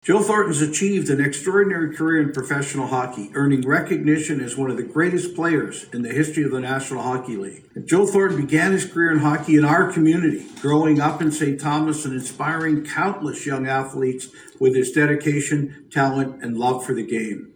In reading the proclamation at city hall, Preston praised Thornton for his dedication, talent and love of the game.